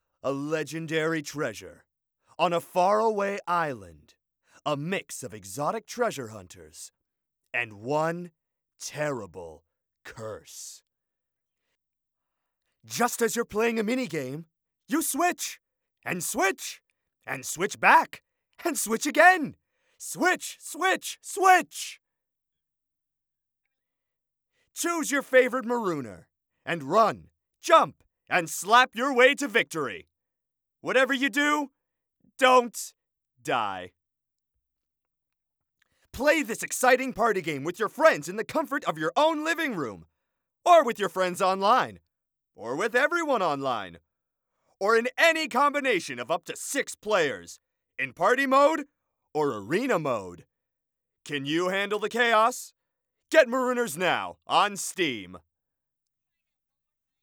MaroonersTrailer-VO2.wav